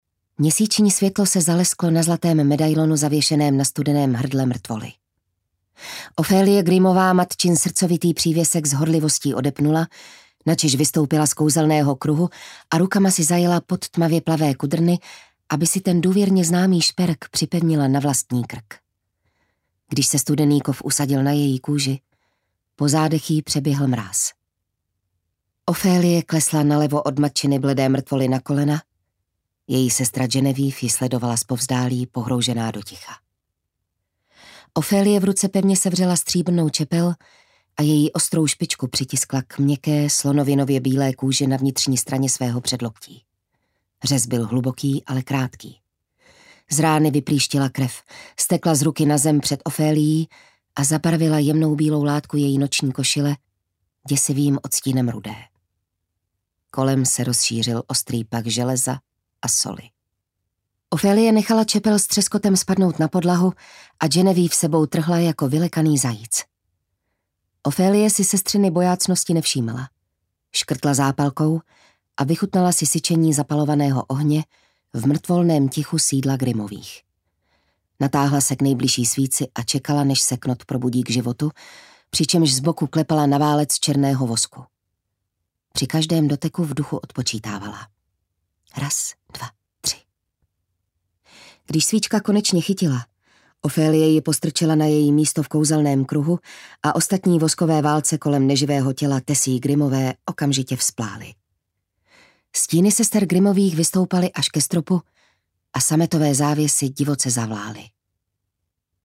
Fantasma audiokniha
Ukázka z knihy